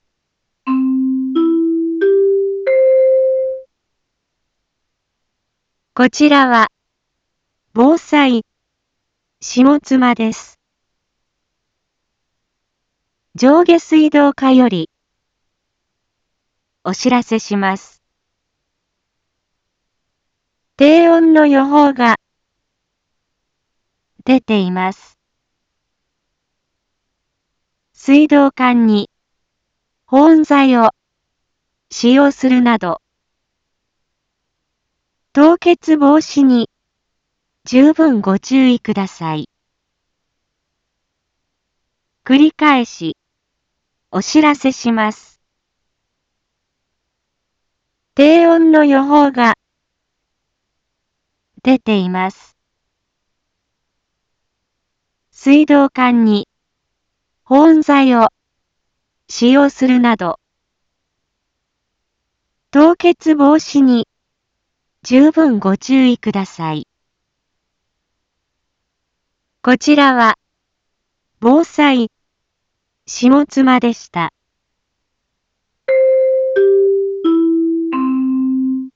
一般放送情報
Back Home 一般放送情報 音声放送 再生 一般放送情報 登録日時：2022-02-10 16:01:23 タイトル：凍結防止対策のお願い インフォメーション：こちらは、防災下妻です。